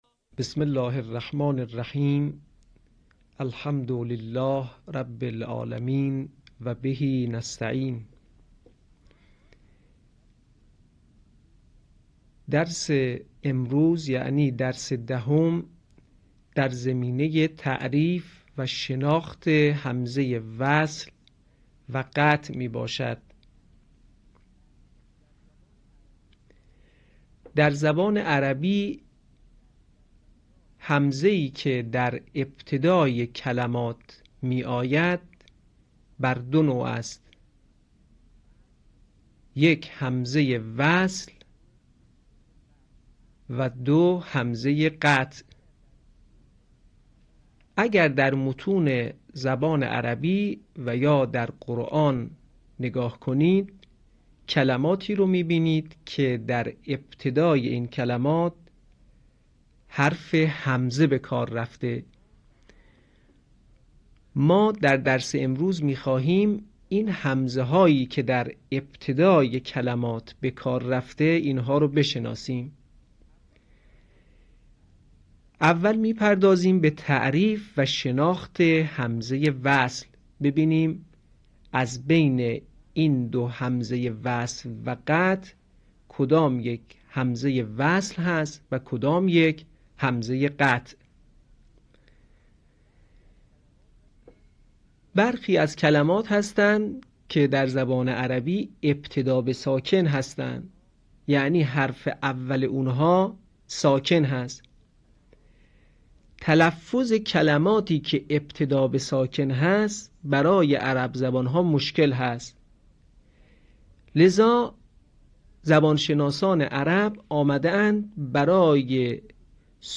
صوت | آموزش انواع همزه